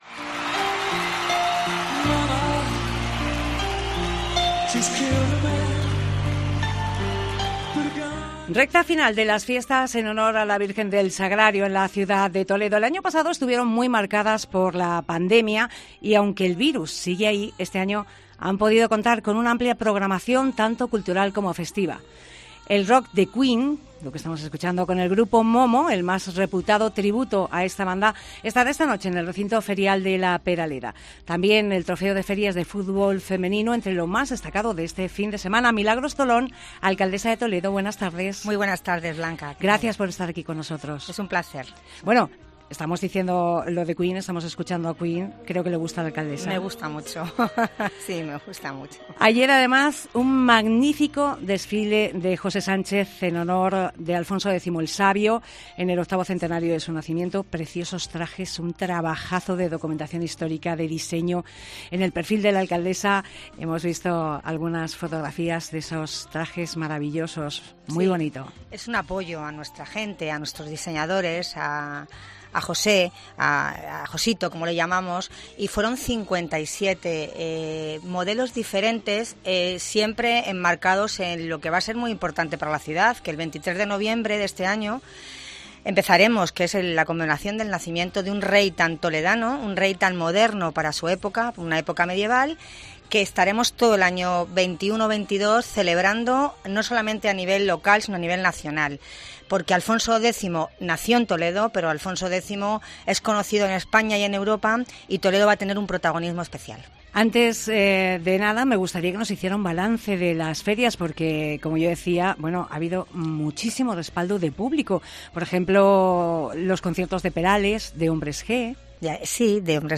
Entrevista a Milagros Tolón, alcaldesa de Toledo